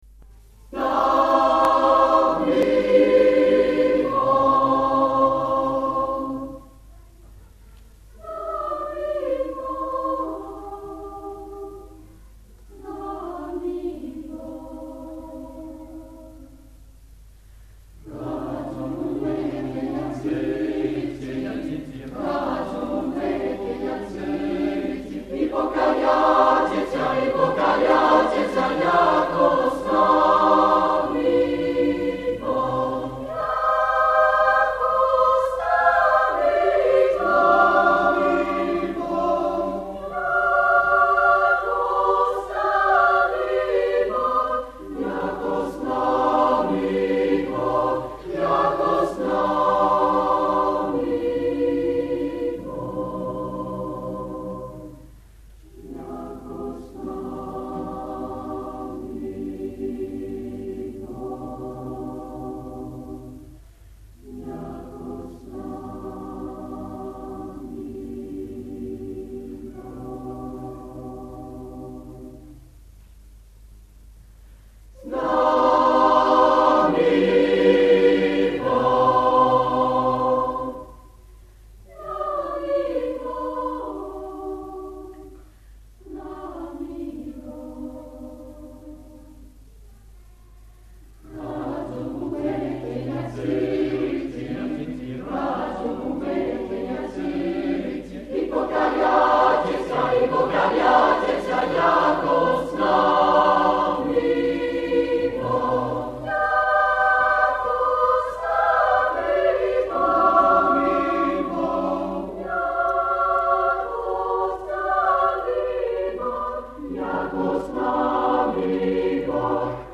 Eglise Notre Dame de la Paix MACON
Extraits du concert